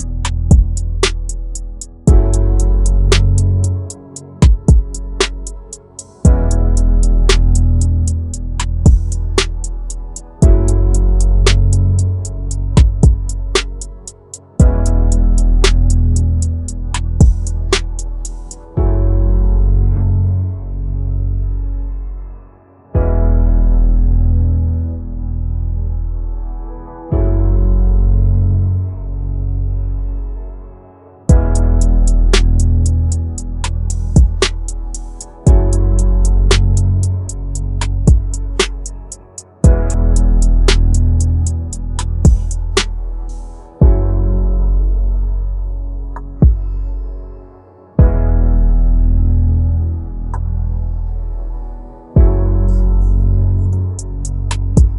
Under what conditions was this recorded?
Stereo · 16-bit · .wav